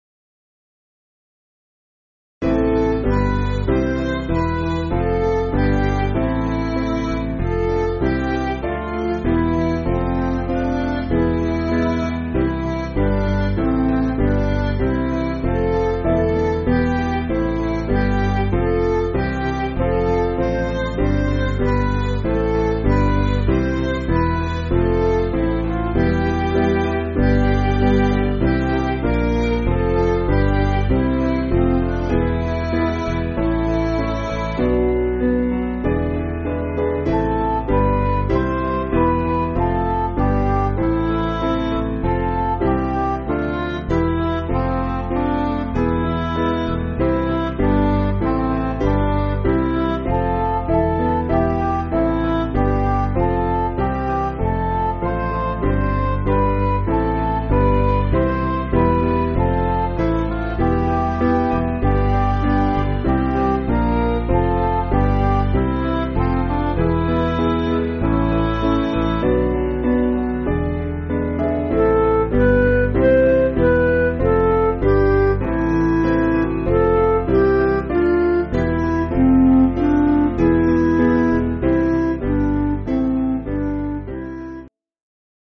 Piano & Instrumental
(CM)   5/Am